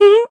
scared-step.ogg.mp3